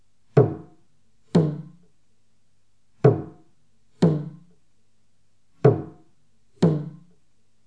4.1.2.2. TRỐNG CƠM
Bịt: dùng 1 tay chặn trên mặt trống, ngón tay kia đánh, mặt kia không bịt.